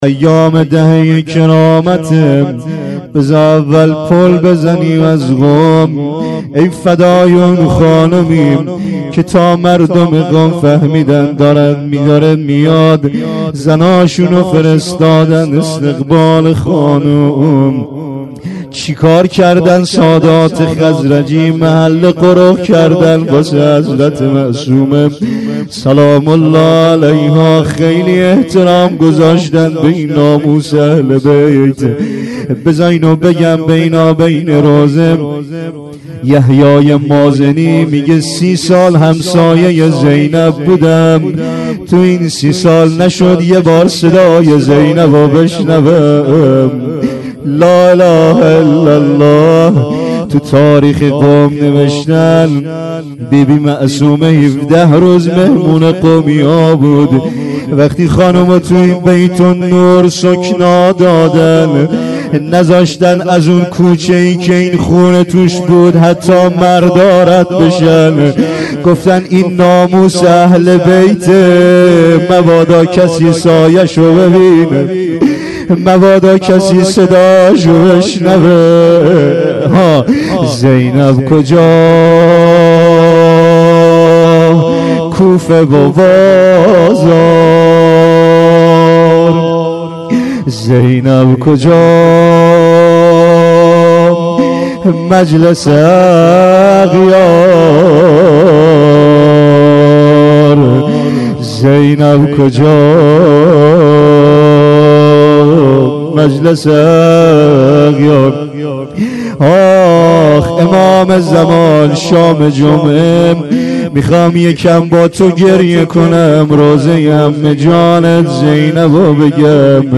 rozeh 93.06.14.mp3